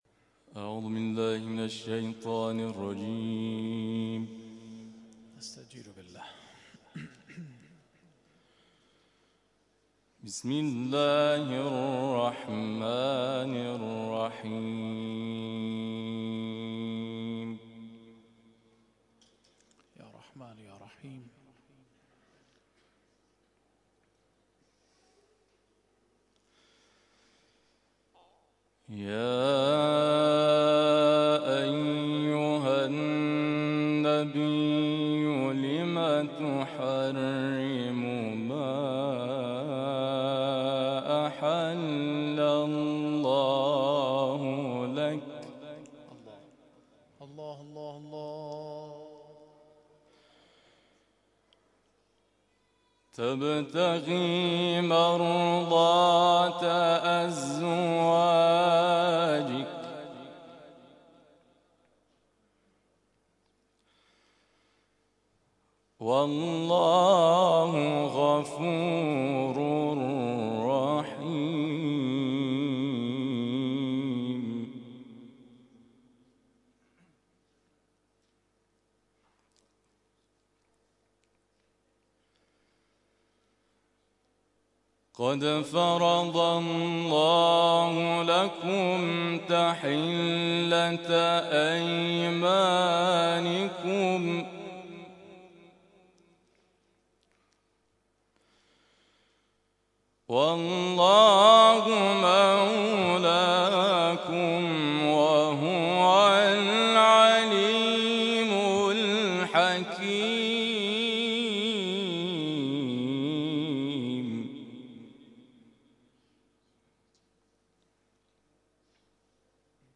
تلاوت
گروه جلسات و محافل: محفل انس با قرآن کریم این هفته آستان عبدالعظیم الحسنی(ع) با تلاوت قاریان ممتاز و بین‌المللی کشورمان برگزار شد.